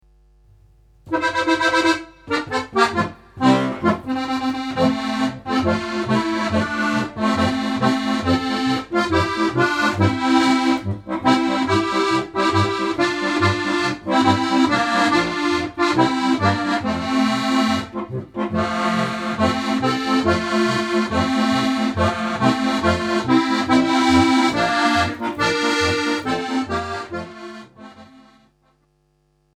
Besetzung: Steirische Harmonika